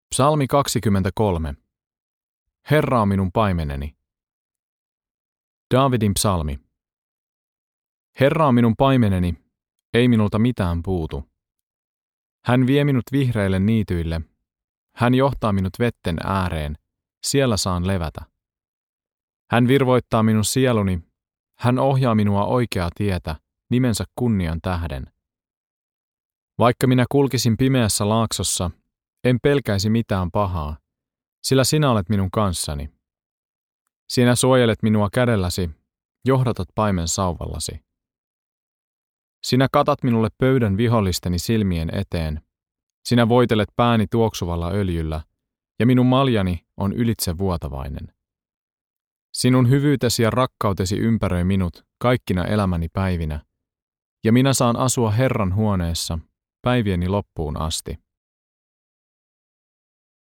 Suomalainen Ääniraamattu - Sulje silmäsi ja kuuntele